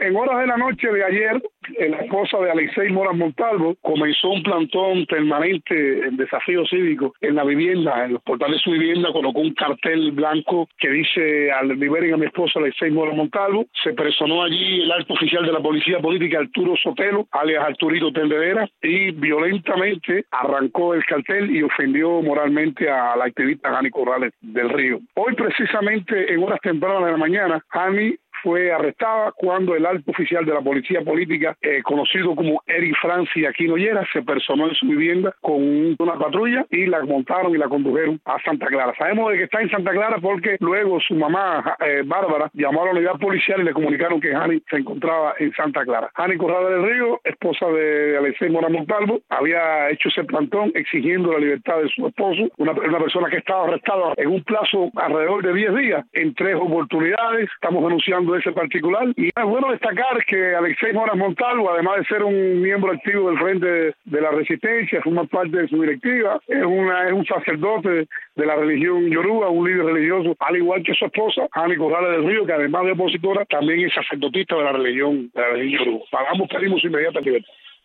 [Con entrevista de Radio Martí e información de redes sociales]